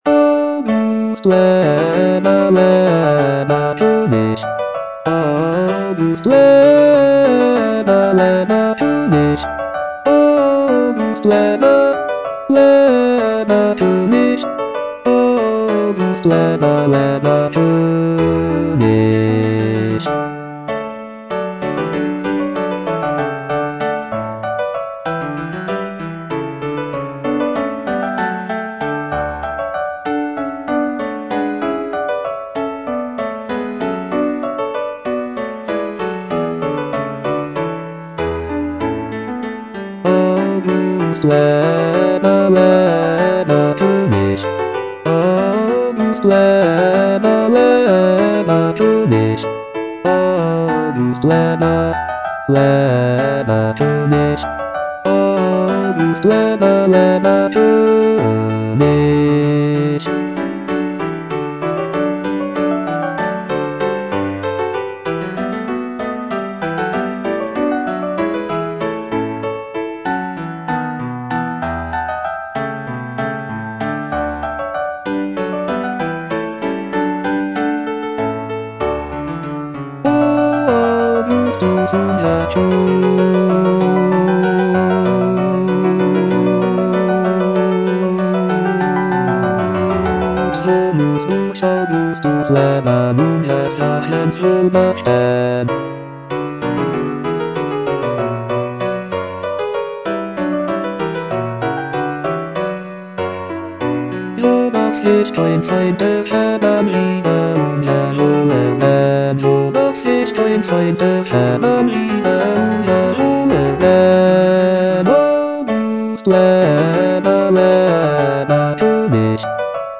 Choralplayer playing Cantata
It is a festive and celebratory work featuring lively trumpet fanfares and joyful choruses.